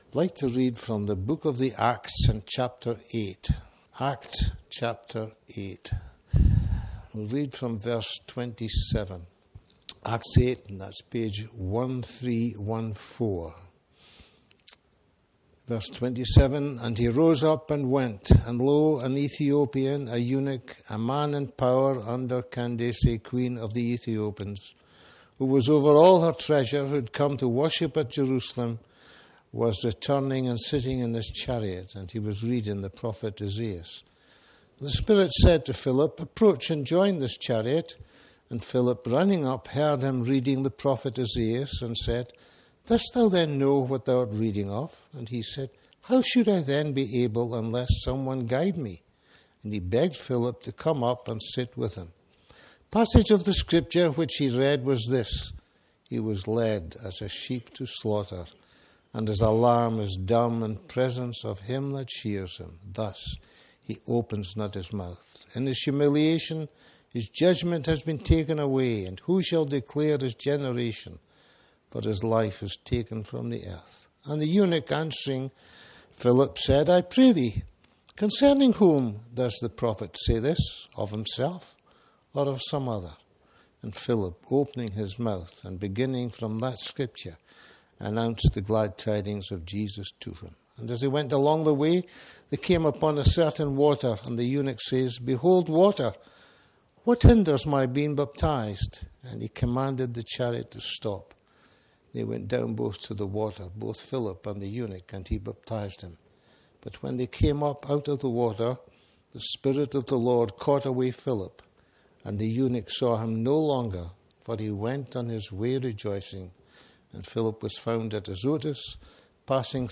This Gospel preaching speaks to the emptiness that success, pleasure, and religion can’t fill. Only Jesus Christ offers the lasting satisfaction your soul longs for.